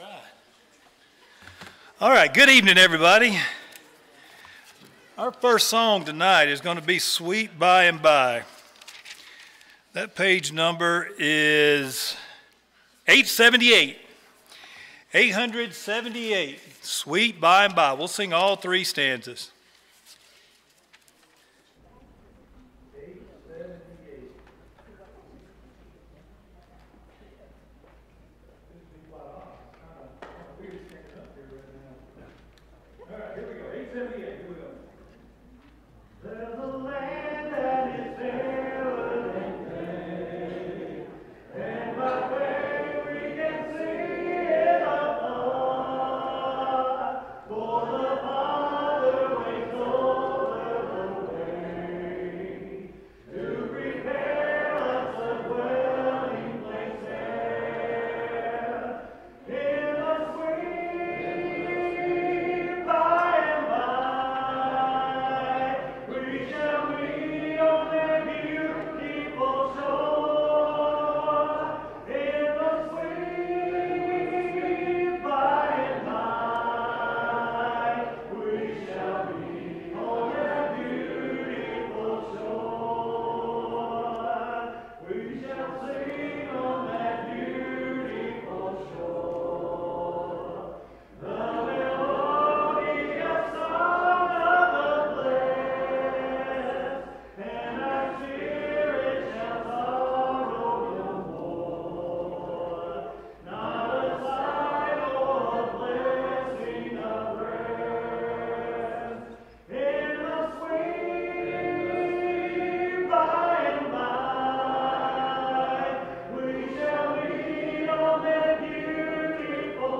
Series: Sunday PM Service